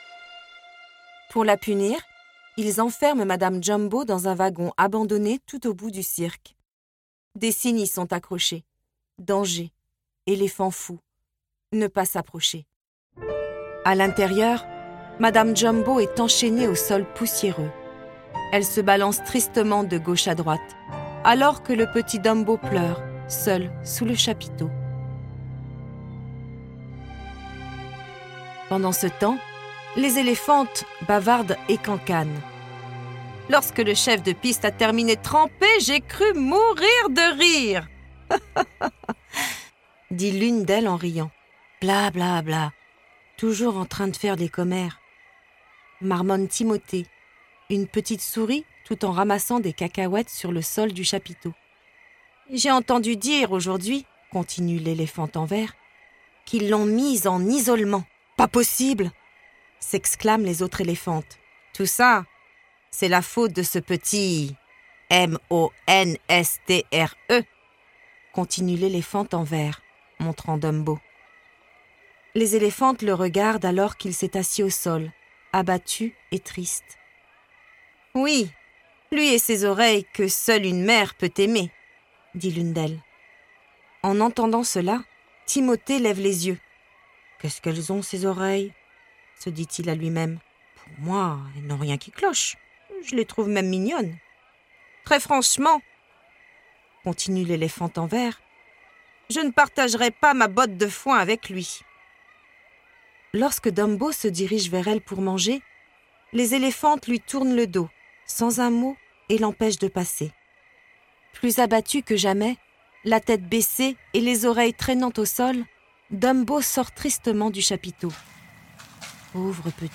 04 - Chapitre 04_ Dumbo - L'histoire à écouter_ Dumbo.flac